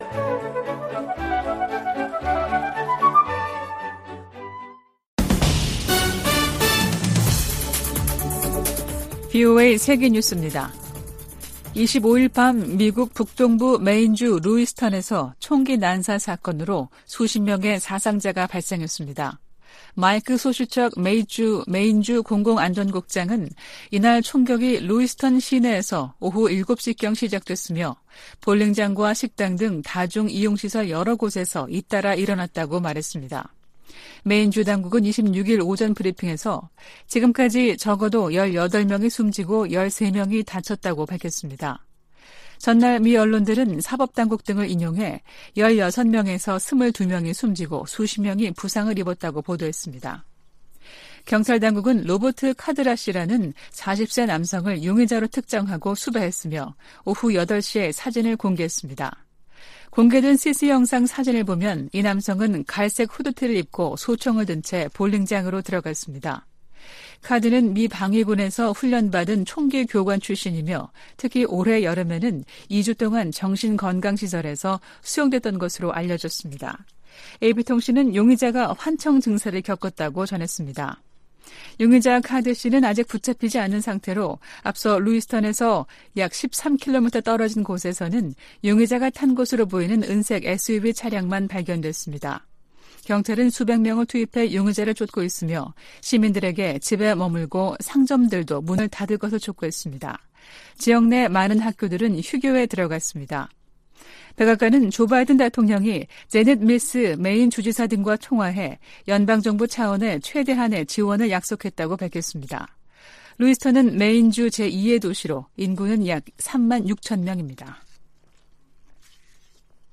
VOA 한국어 아침 뉴스 프로그램 '워싱턴 뉴스 광장' 2023년 10월 27일 방송입니다. 미국, 한국, 일본 세 나라 외교장관들이 북한과 러시아 간 불법 무기 거래를 규탄하는 공동성명을 발표했습니다. 북한은 유엔에서 정당한 우주 개발 권리를 주장하며 사실상 우주발사체 발사 시도를 계속하겠다는 뜻을 내비쳤습니다.